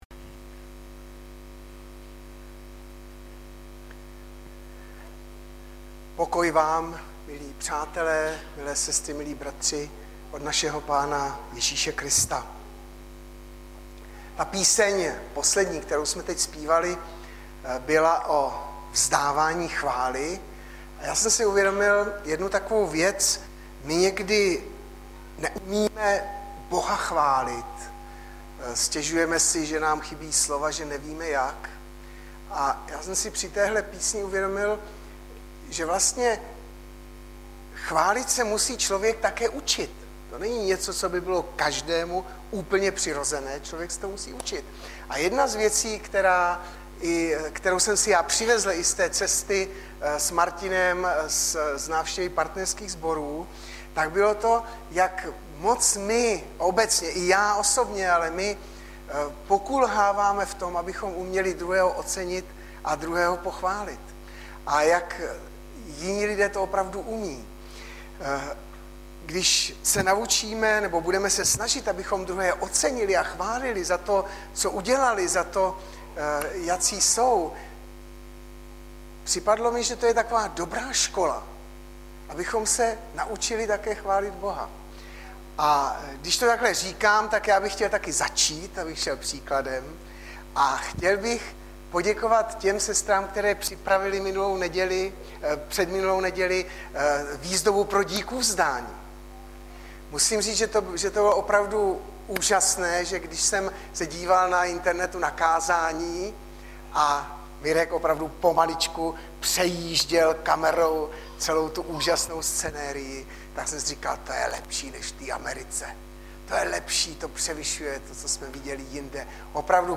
Webové stránky Sboru Bratrské jednoty v Litoměřicích.
POVOLEBNÍ KÁZÁNÍ